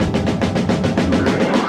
Chopped Fill 3.wav